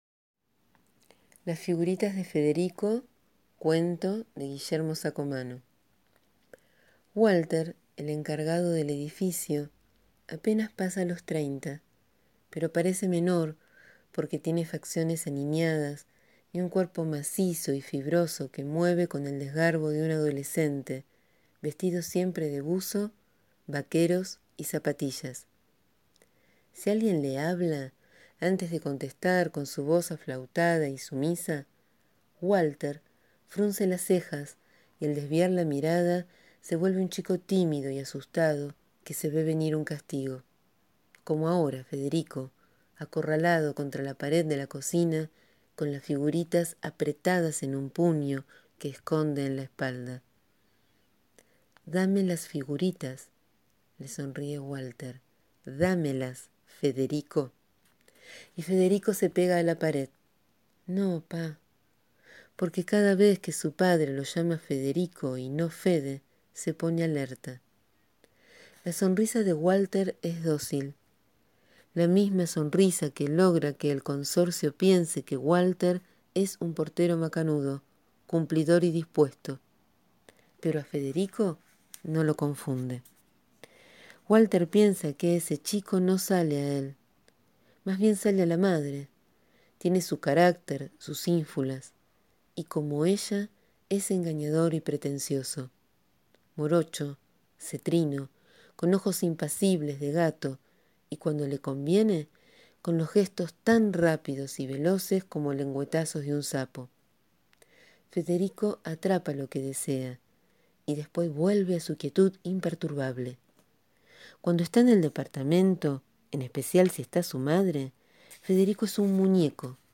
Hoy te leo este gran cuento de Guillermo Saccomanno (Argentina 1948). Un texto que traza el ejercicio del poder en las relaciones familiares y laborales.